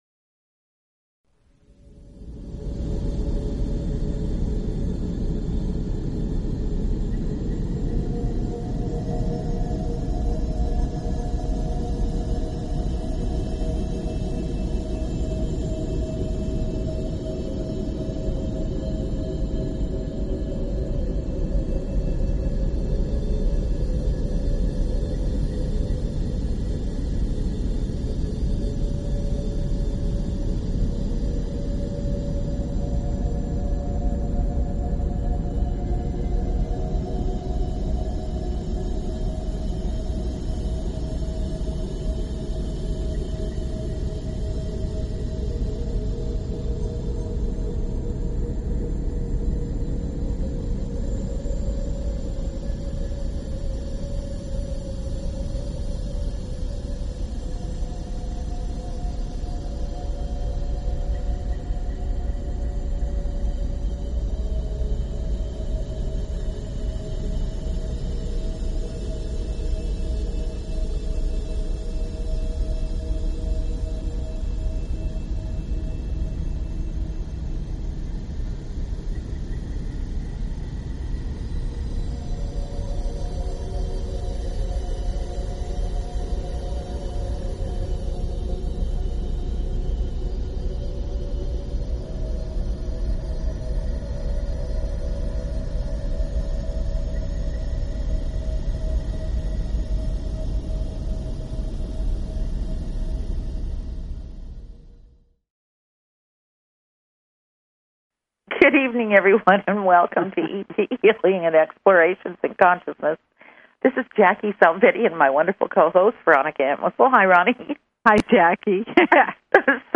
Talk Show Episode, Audio Podcast, ET_Healing and Courtesy of BBS Radio on , show guests , about , categorized as